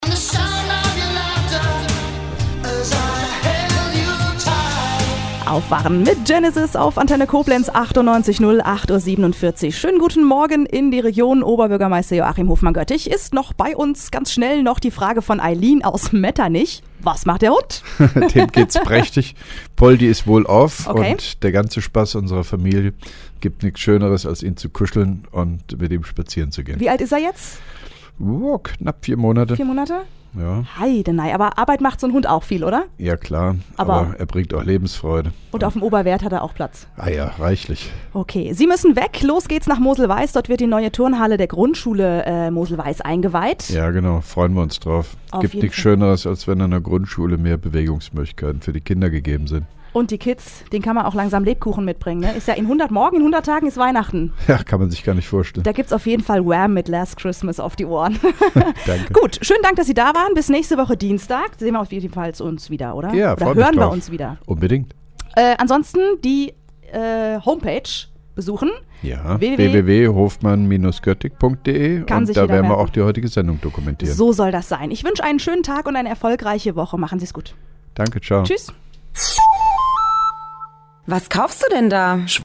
(4) Koblenzer OB Radio-Bürgersprechstunde 14.09.2010